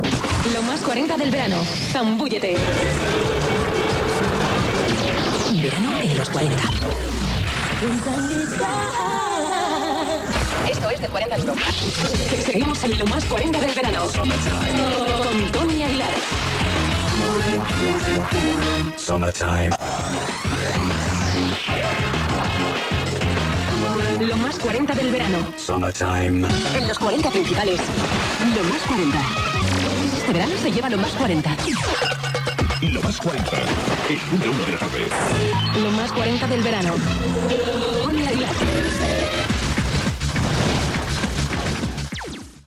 Indicatiu del programa
FM